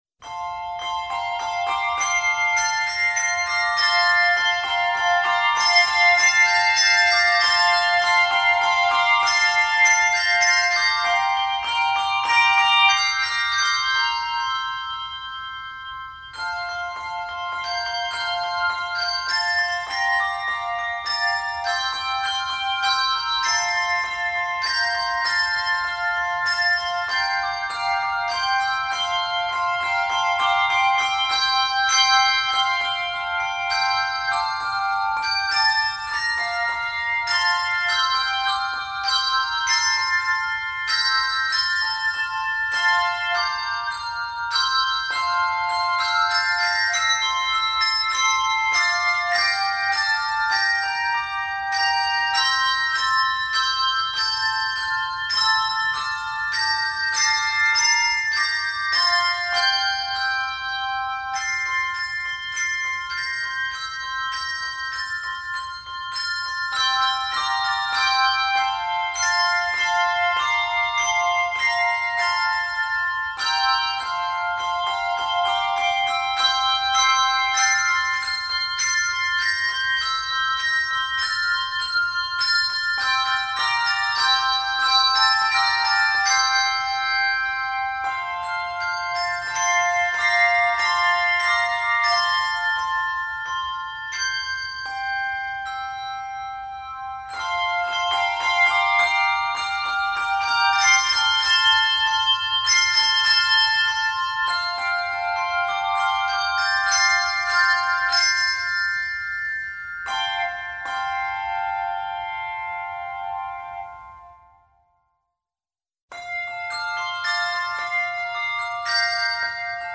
Each piece uses only twelve bells, spanning F5 to C7.
Scored for 3 octave handbells or handchimes.